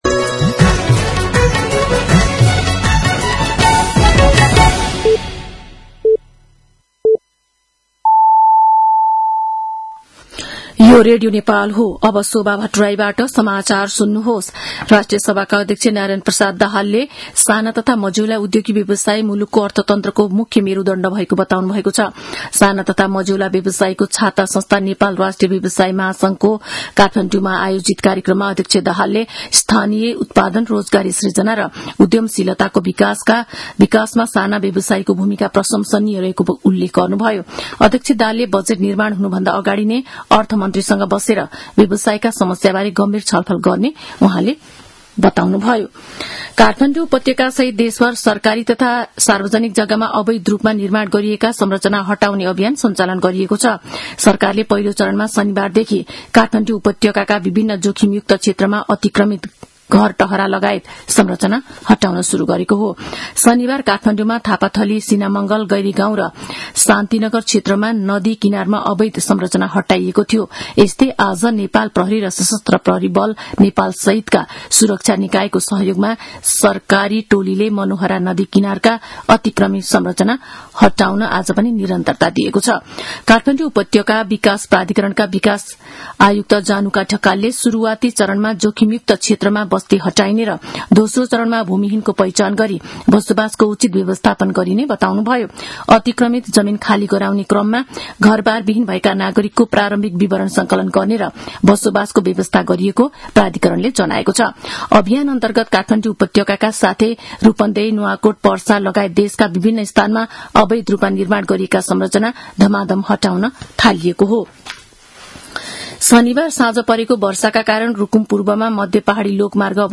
साँझ ५ बजेको नेपाली समाचार : १३ वैशाख , २०८३